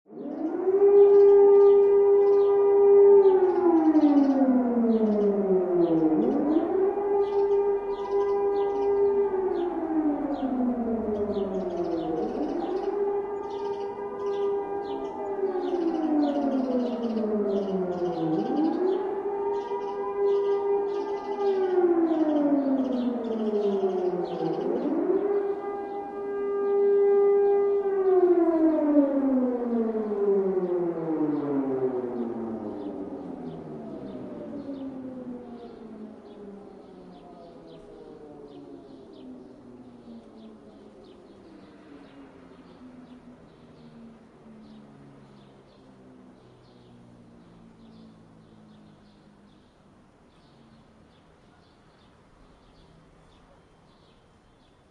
Sound Effects
Siren Air Raid Birds